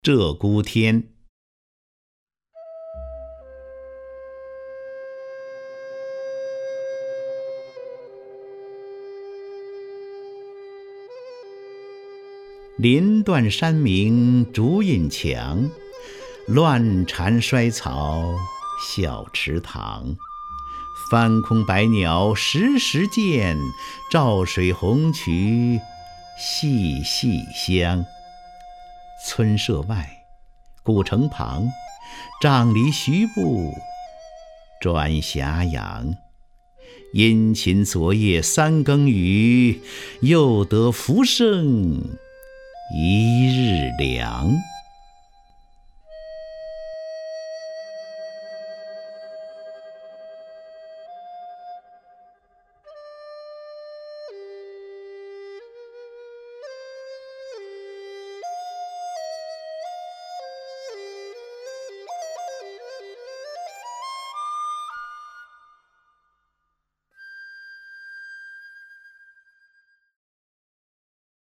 首页 视听 名家朗诵欣赏 张家声
张家声朗诵：《鹧鸪天·林断山明竹隐墙》(（北宋）苏轼)　/ （北宋）苏轼
ZheGuTianLinDuanShanMingZhuYinQiang_SuShi(ZhangJiaSheng).mp3